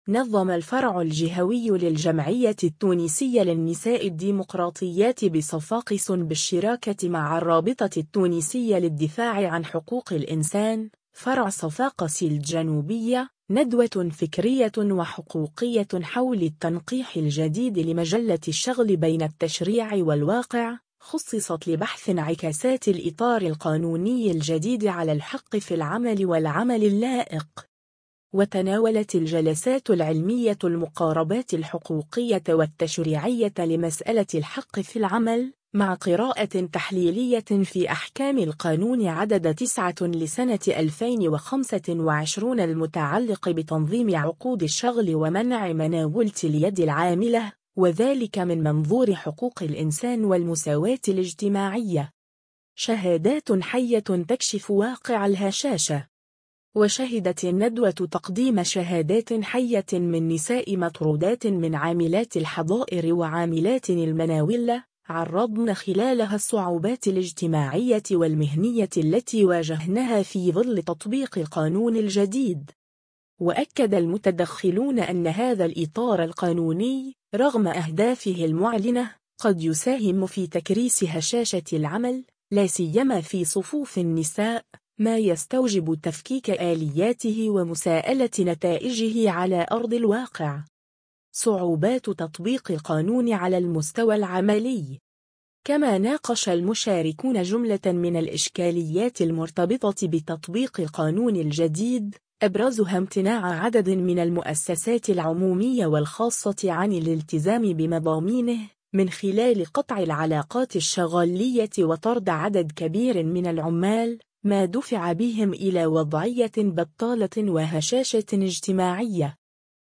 صفاقس : ندوة حول التنقيح الجديد لمجلة الشغل “بين التشريع والواقع ” [فيديو]
وشهدت الندوة تقديم شهادات حية من نساء مطرودات من عاملات الحضائر وعاملات المناولة، عرضن خلالها الصعوبات الاجتماعية والمهنية التي واجهنها في ظل تطبيق القانون الجديد.